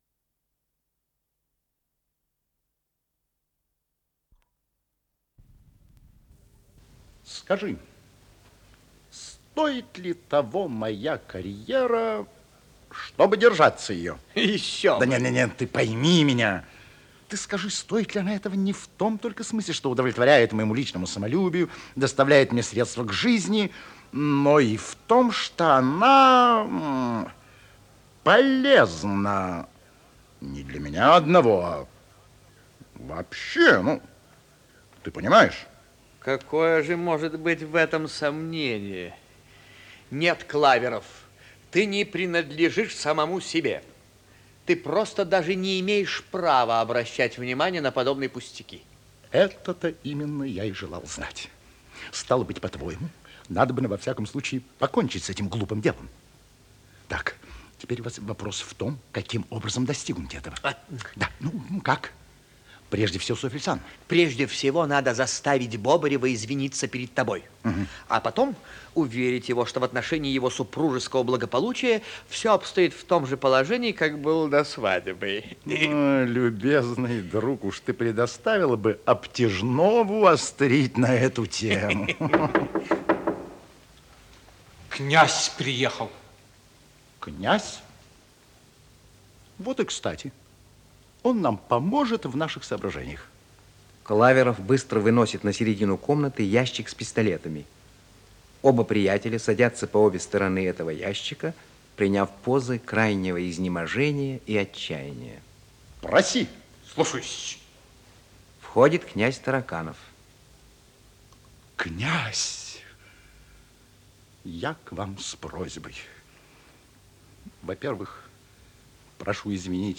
Исполнитель: Артисты государственного ленинградского нового театра